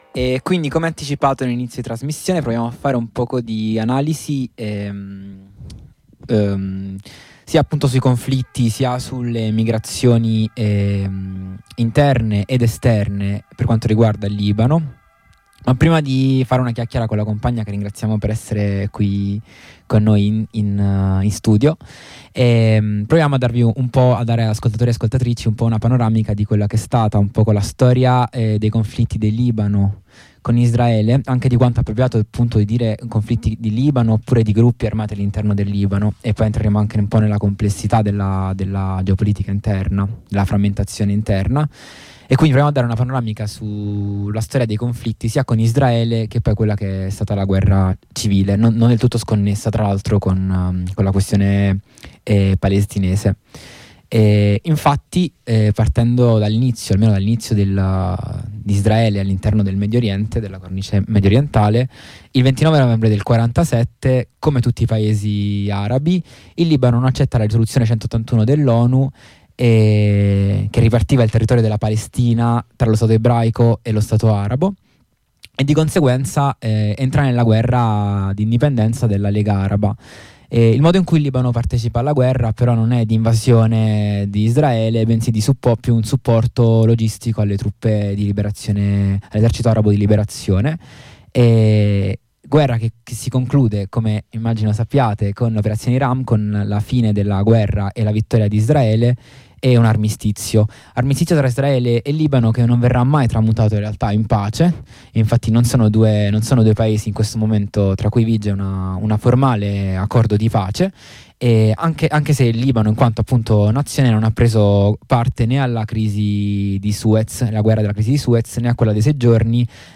In questa puntata di Harraga, in onda su Radio Blackout proviamo a restituire la complessità del contesto libanese grazie al contributo di una compagna che da anni vive a Beirut.